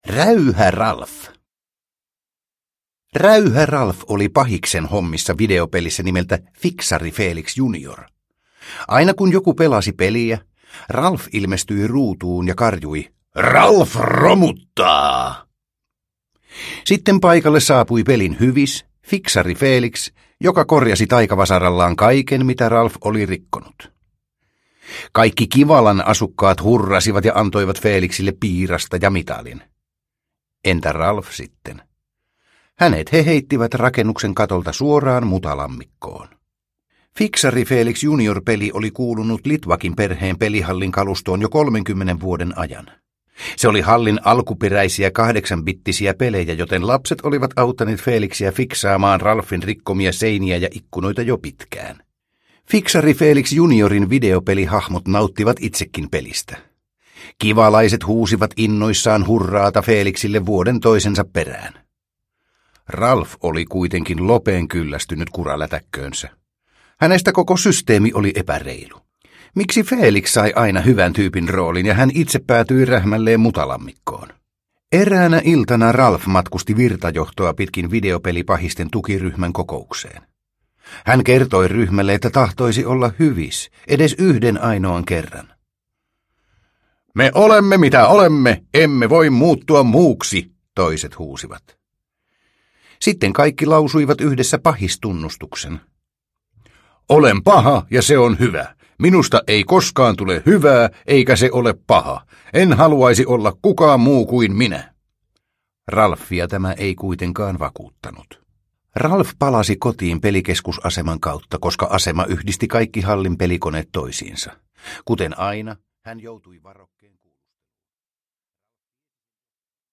Räyhä-Ralf – Ljudbok – Laddas ner